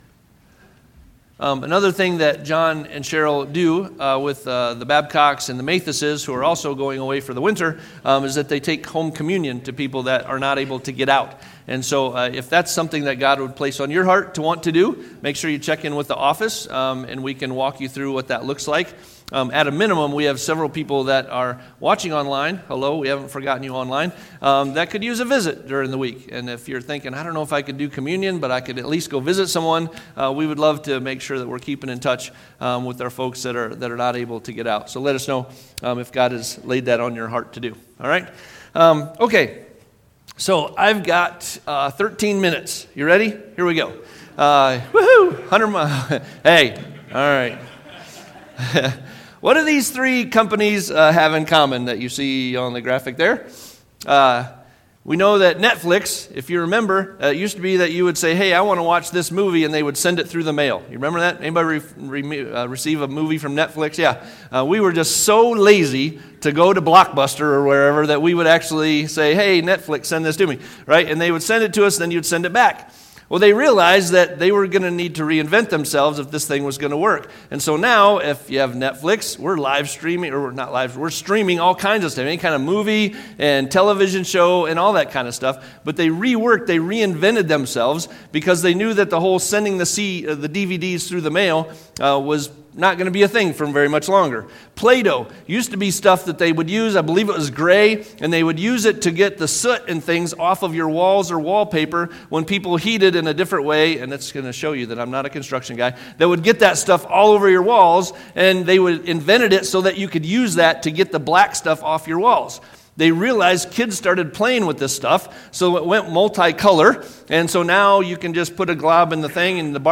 Sermon on transitions to make in 2026